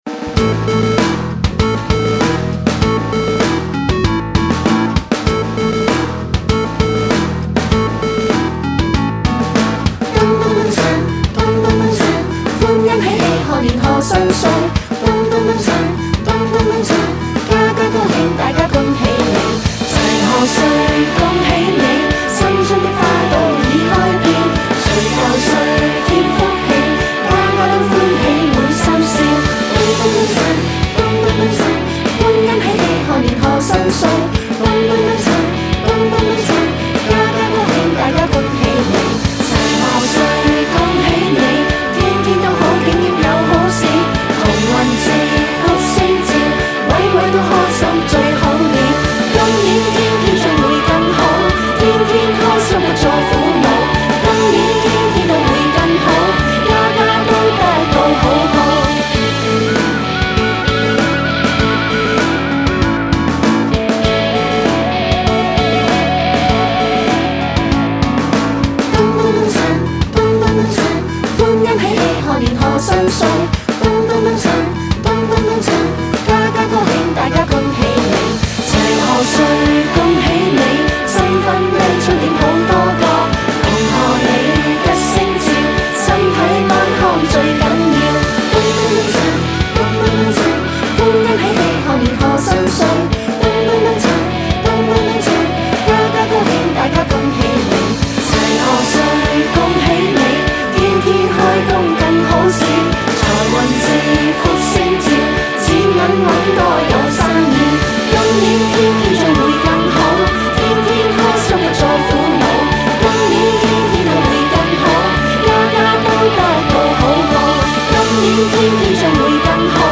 在這麼簡單重覆兼兒歌向的旋律裏，也不一定要隨隨意意編上一個行貨編曲的。